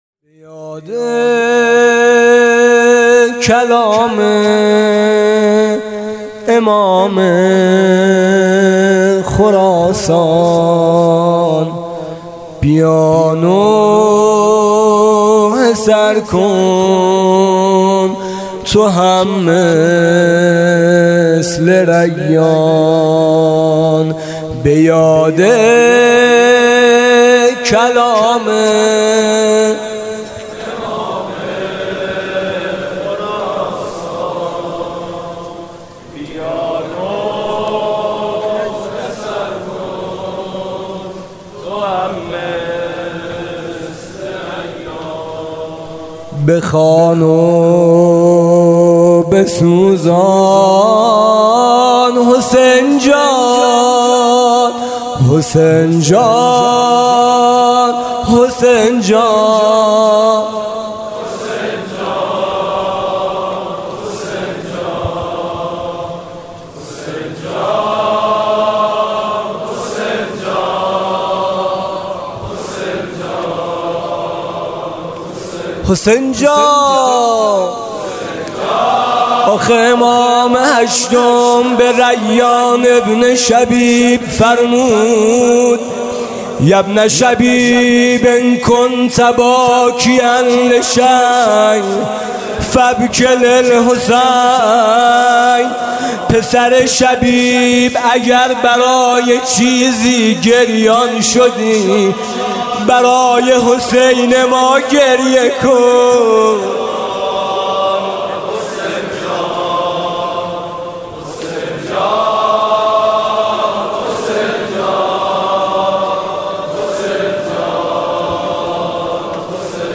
حاج میثم مطیعی/شب دوم محرم الحرام 95/هیئت میثاق با شهدا
زمزمه/آه به کربلا